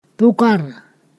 [deteʔ] particle only-1-post-glottal